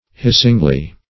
hissingly - definition of hissingly - synonyms, pronunciation, spelling from Free Dictionary Search Result for " hissingly" : The Collaborative International Dictionary of English v.0.48: Hissingly \Hiss"ing*ly\, adv.